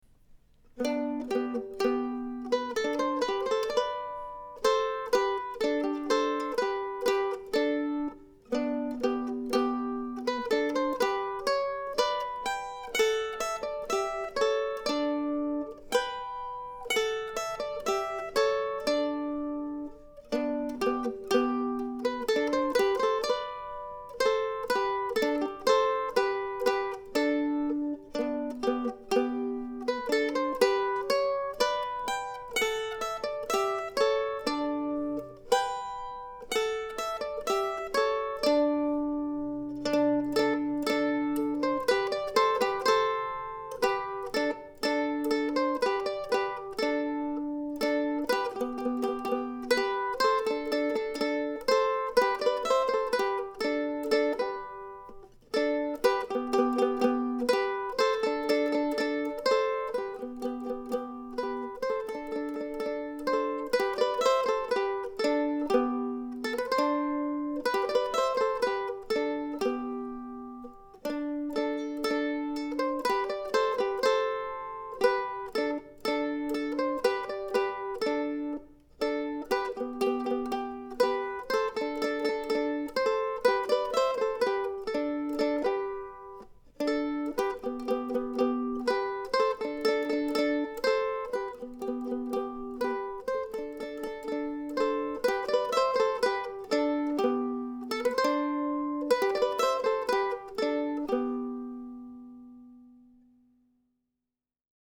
I used this mando for this week's recordings as well, along with a new set of Thomastik strings.